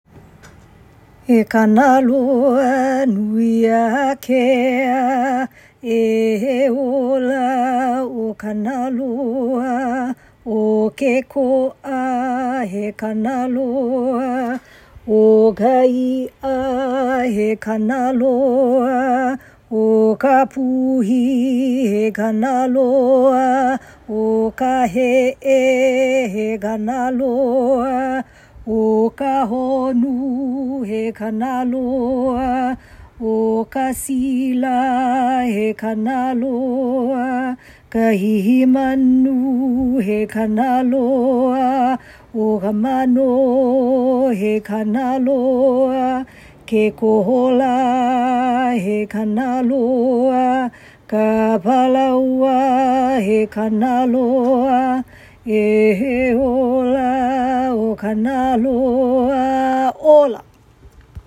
chant), and pule (intentions or prayers).
Kanaloanuiakea-kinolau-oli.m4a